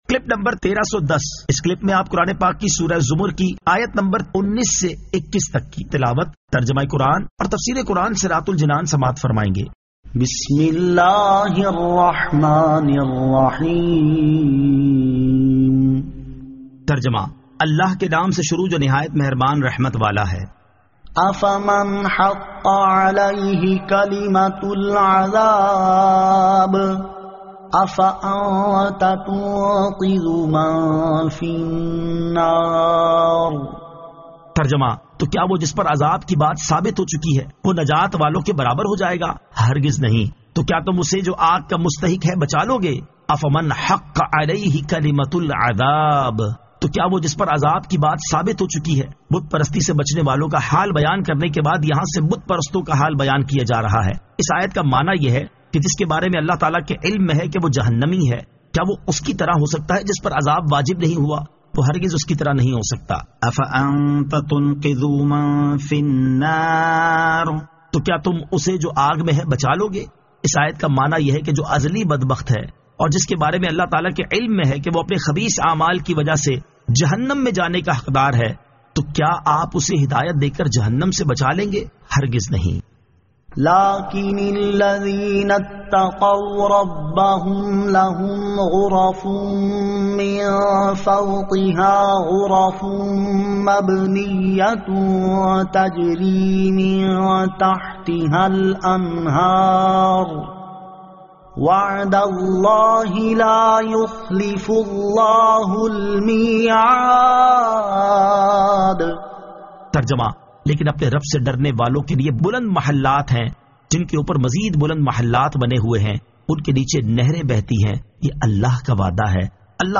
Surah Az-Zamar 19 To 21 Tilawat , Tarjama , Tafseer